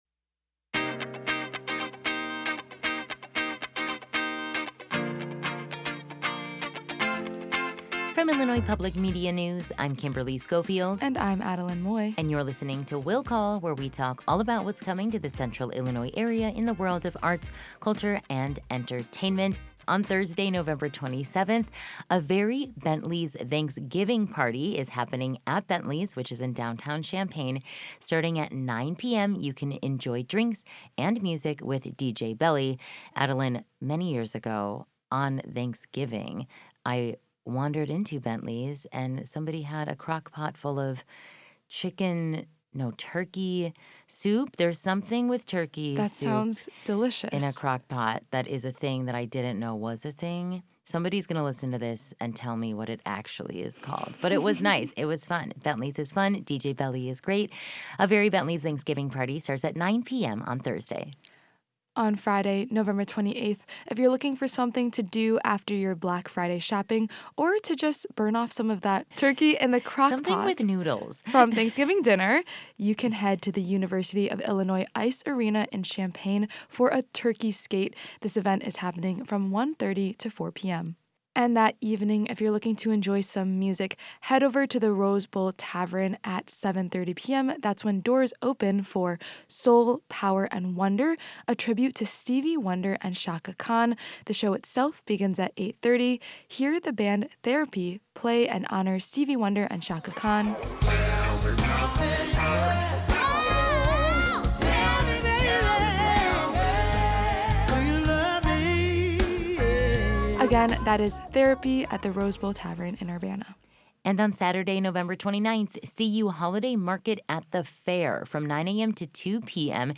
talk about weekend events on IPM News AM 580 and FM 90.9 – Wednesdays at 6:45 and 8:45 a.m., and Thursdays at 5:44 p.m.